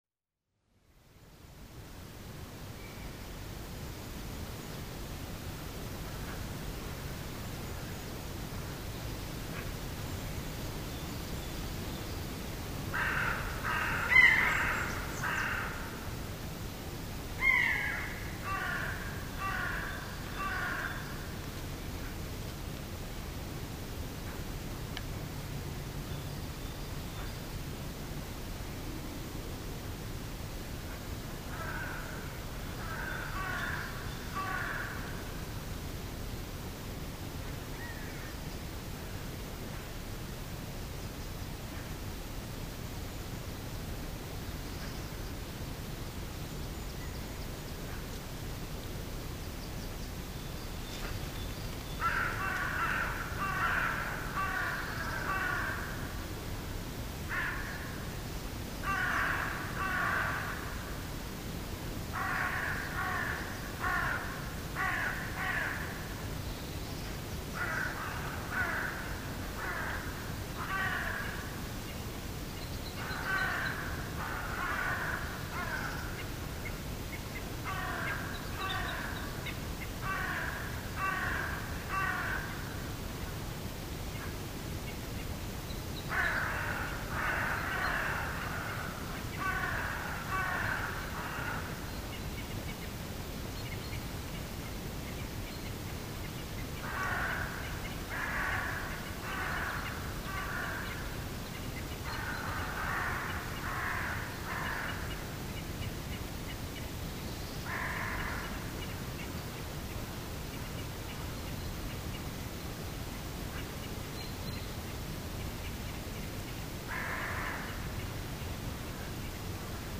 Bos, kwekkende raaf, vogels in de verte: